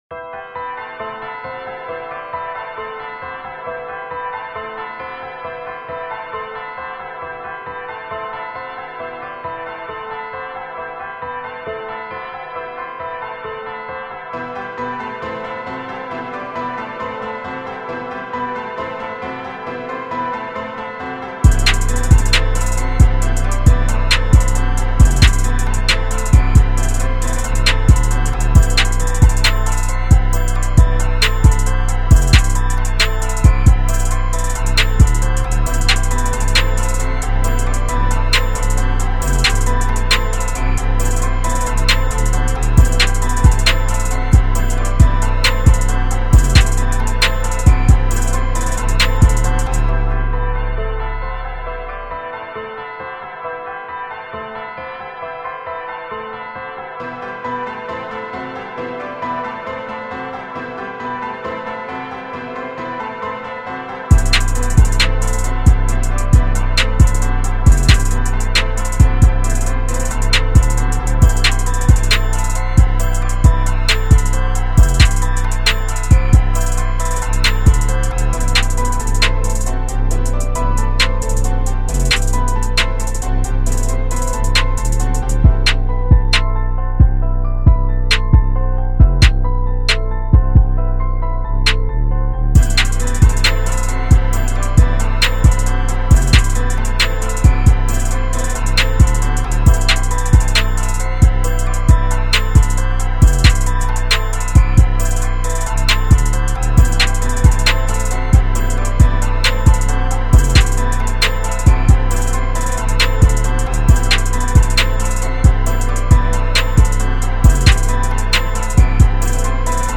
Drill Instrumental